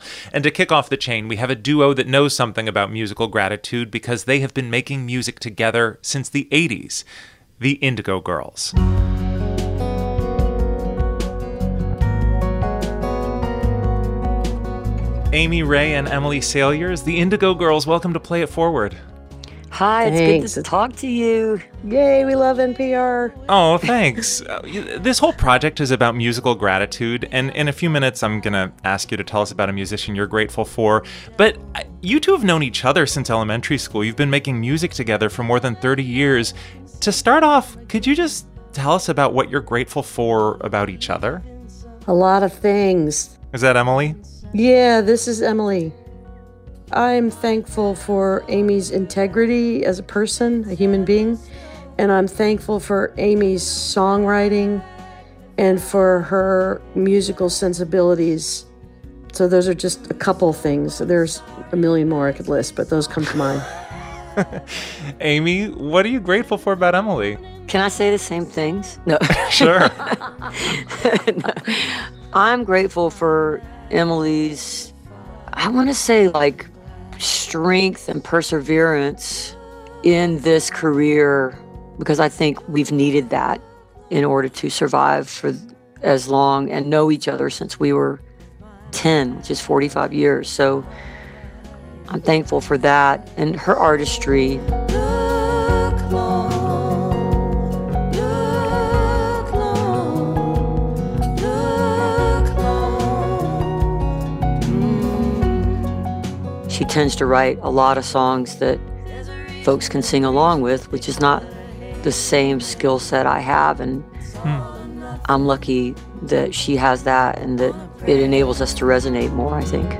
(captured from the webcast)
02. interview (indigo girls) (6:05)